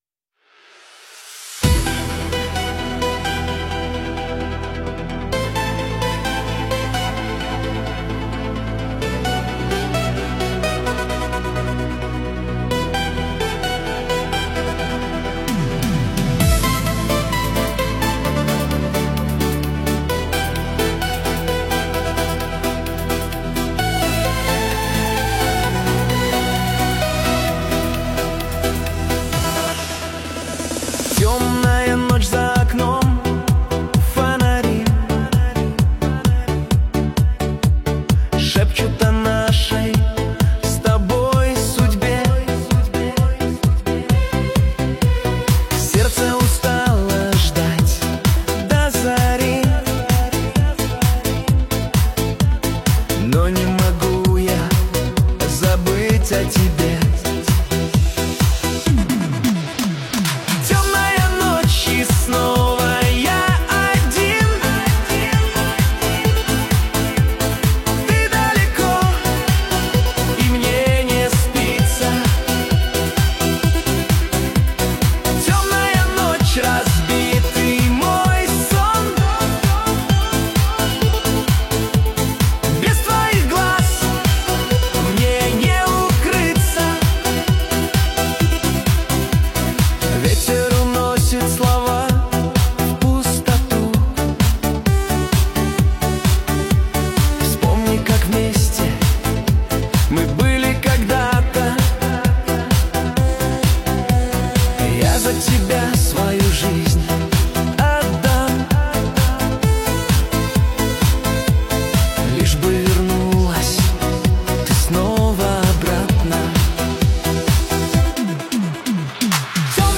Русские песни
ШАНСОН ДЛЯ ДУШИ